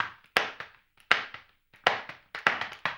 HAMBONE 01.wav